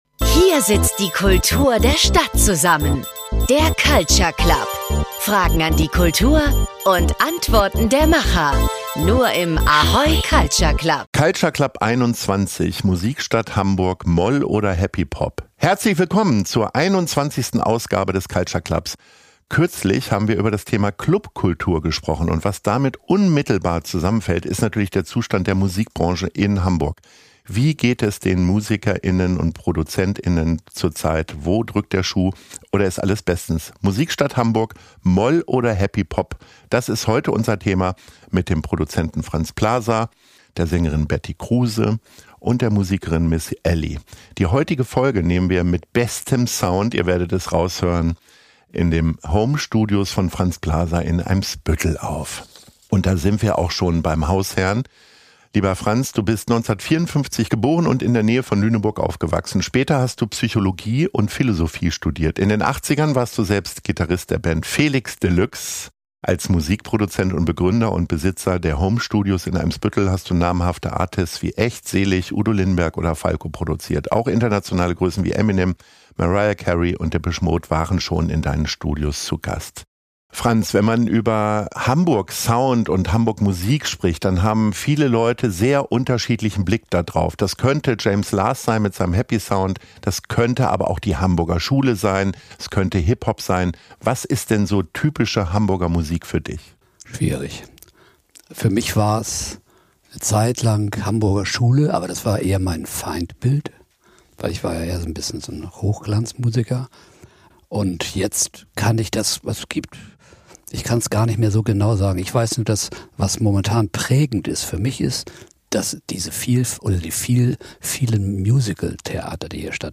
Musikstadt Hamburg – moll oder happy Pop? ~ Culture Club - Der Kulturtalk bei ahoy, präsentiert von hvv switch Podcast